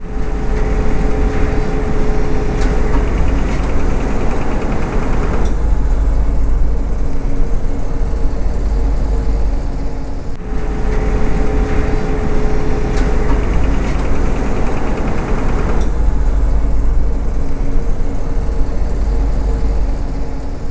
CopyMachine_3.wav